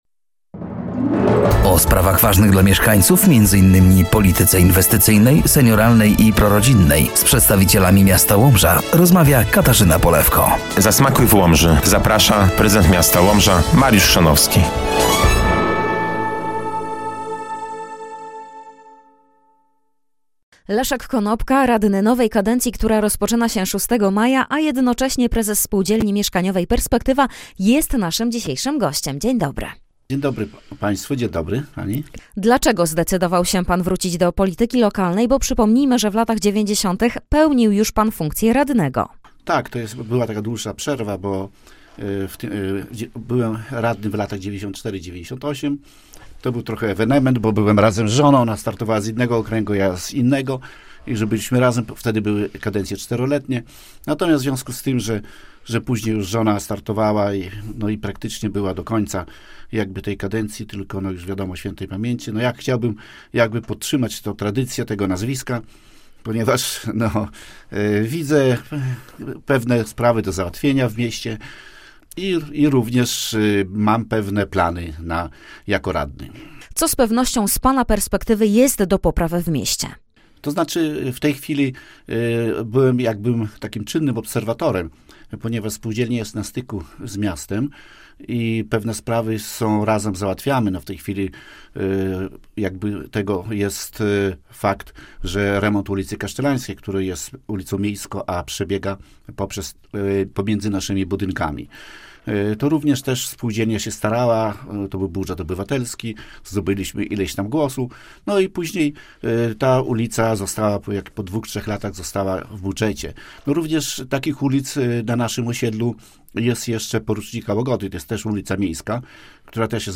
Gościem jedenastej audycji był Leszek Konopka, Prezes Spółdzielni Mieszkaniowej Perspektywa oraz radny nowej kadencji w mieście Łomża.